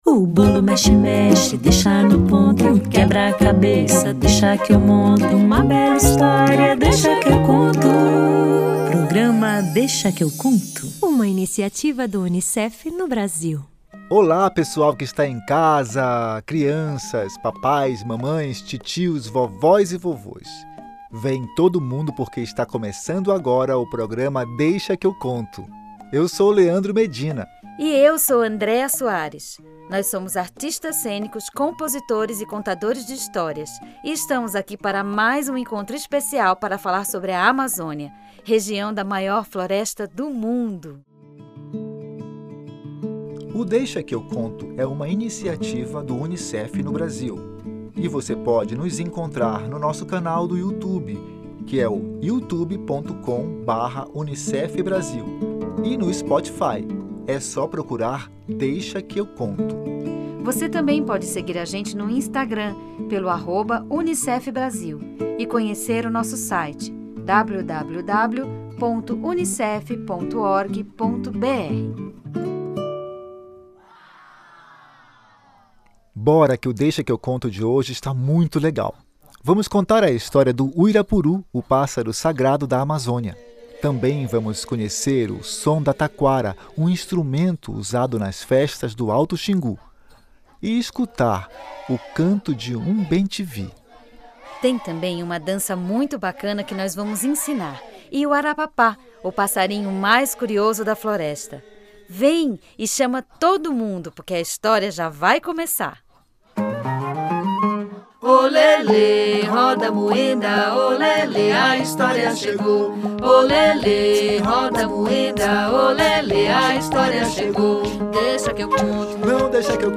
Tem os sons da Amazônia e o arapapá, que vai querer saber da vovó porque o seu amiguinho de quintal anda tão triste.